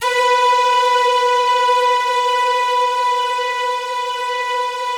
BIGORK.B3 -R.wav